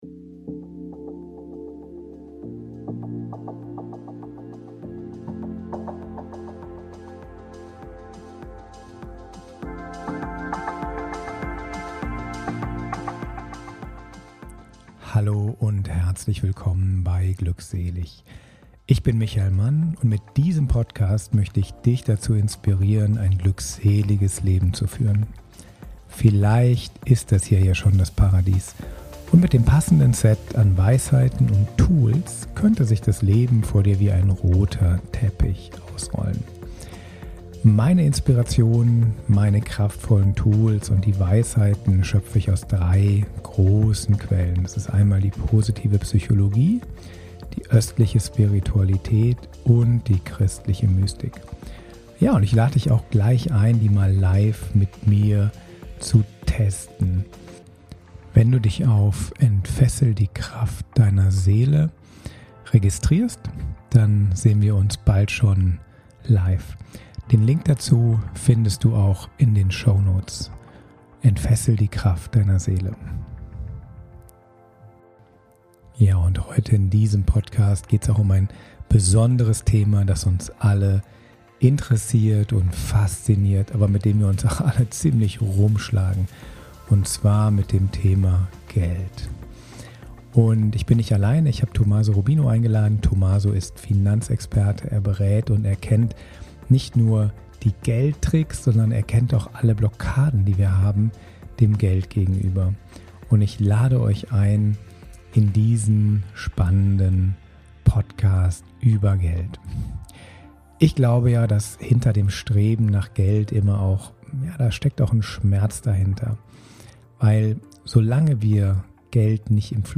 Wie Du entspannt Geld ansparst - Interview